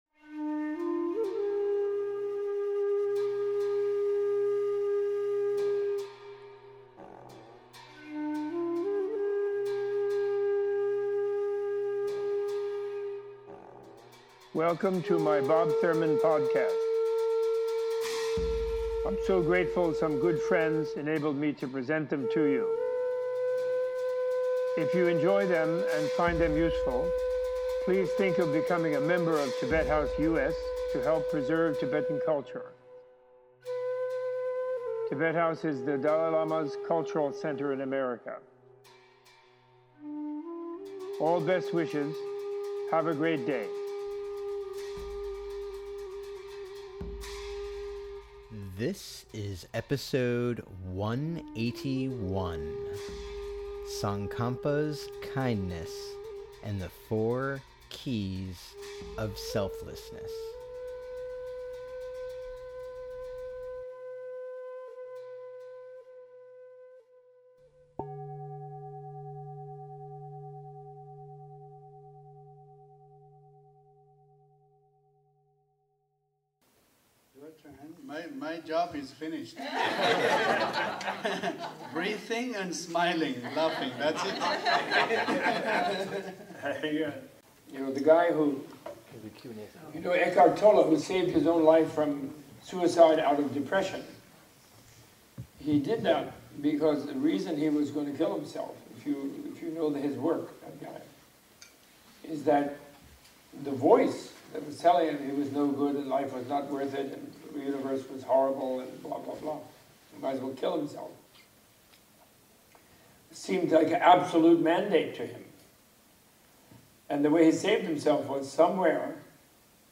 In this two part podcast Professor Thurman discusses the history of Tsongkhapa, the Ganden (Joyous) Community he founded and leads a guided meditation on the Four Keys of Selflessness.